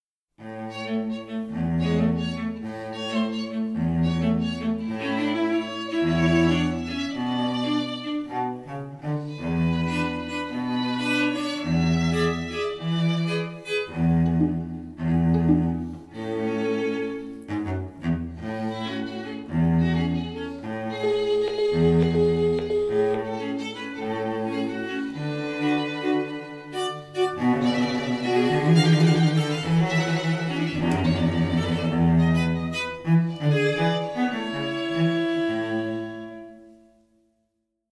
Interpret: kein Solist; N.N., Dirigent